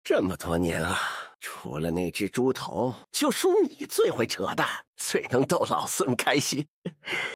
可以听到，剪除之后，所有空白的部分都被剪除了，听起来显得紧凑连贯。